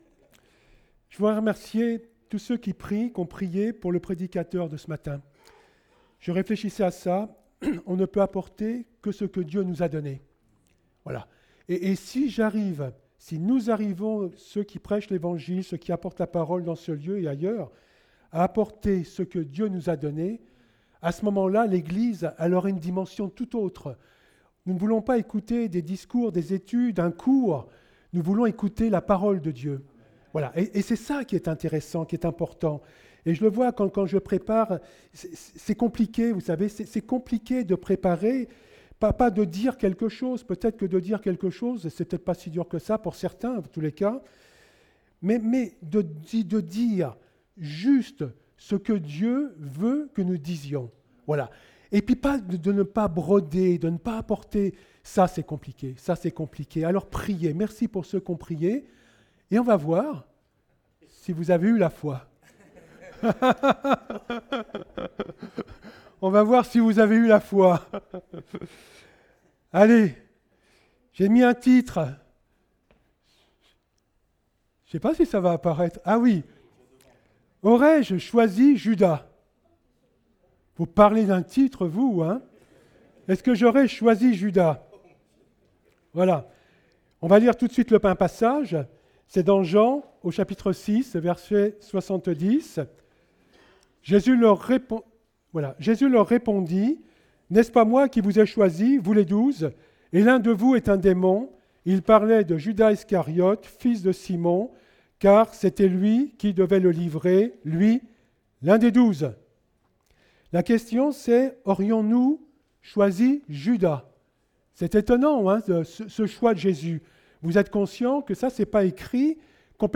Date : 29 juillet 2018 (Culte Dominical)